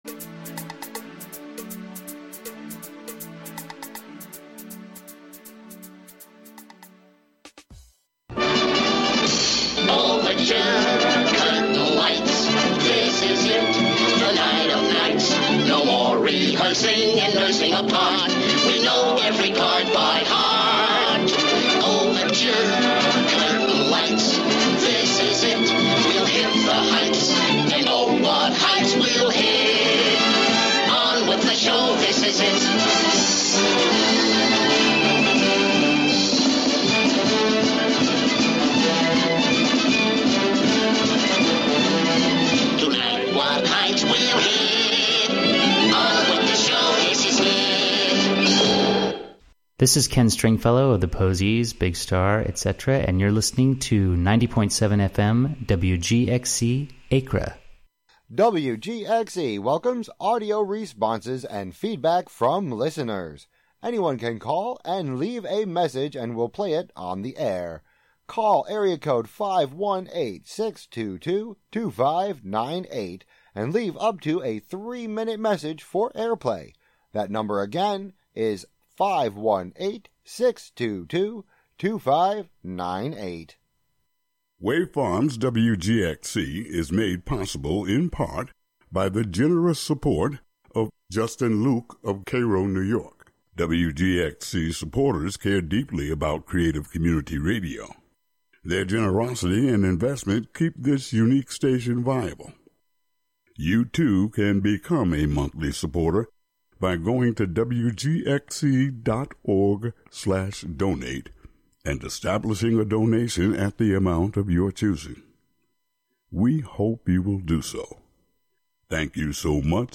Hosted by youth at Greater Hudson Promise Neighbor...
Interview with Mayor Johnson!: Mar 21, 2025: 4pm - 4:30 pm